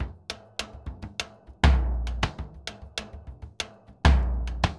Bombo_Salsa 100_1.wav